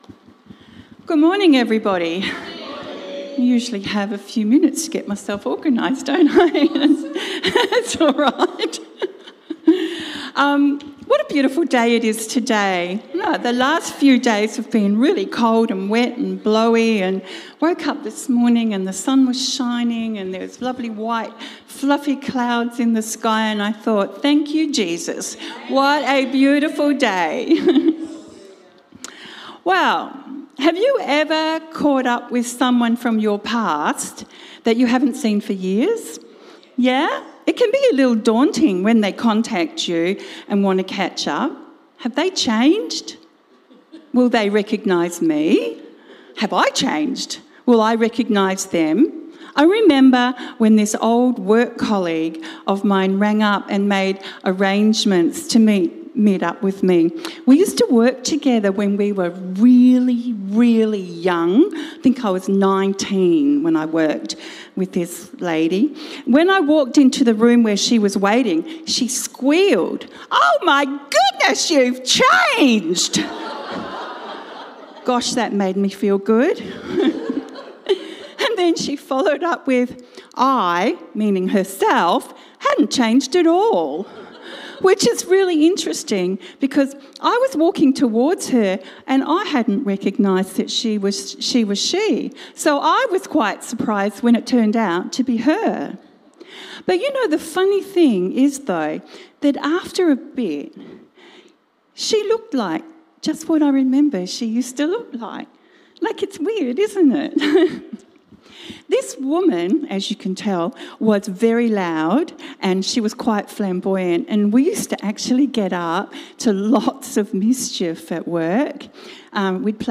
This sermon titled “Remember Me”